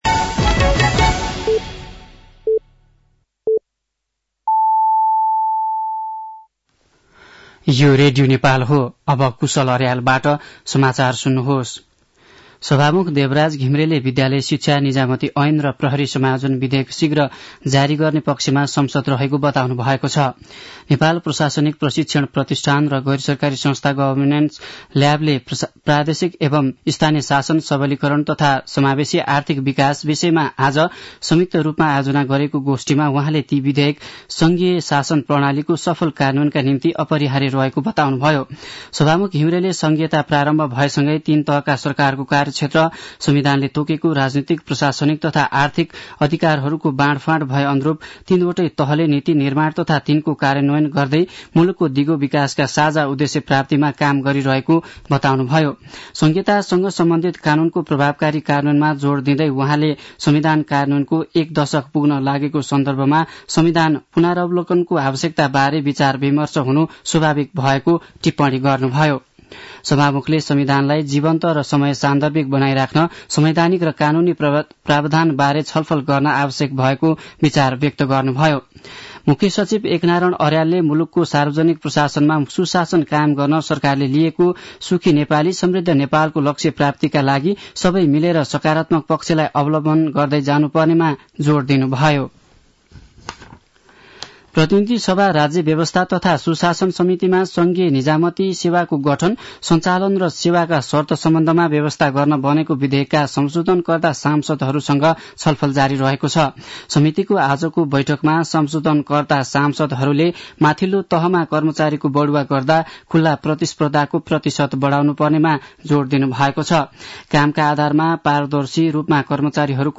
साँझ ५ बजेको नेपाली समाचार : ५ पुष , २०८१
5-pm-nepali-news-9-04.mp3